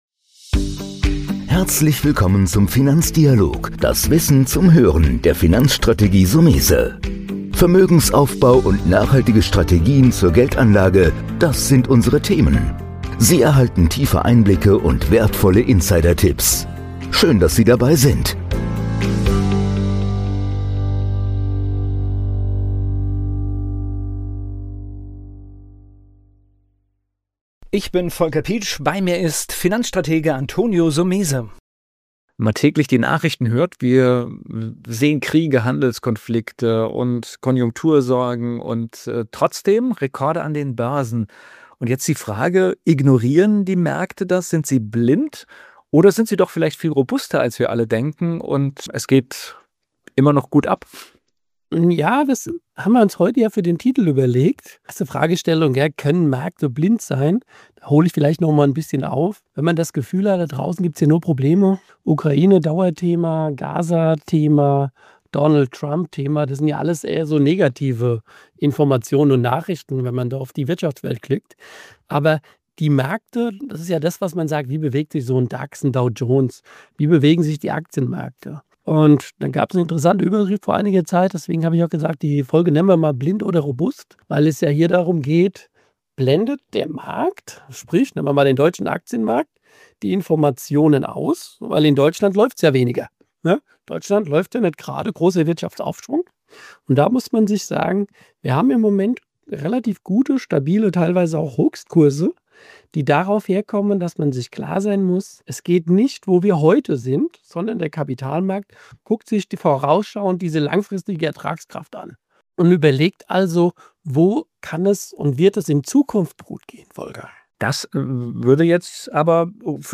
234 | Aktienmärkte – blind oder robust?— Interview